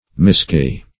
misgie - definition of misgie - synonyms, pronunciation, spelling from Free Dictionary Search Result for " misgie" : The Collaborative International Dictionary of English v.0.48: Misgie \Mis*gie"\, v. t. See Misgye .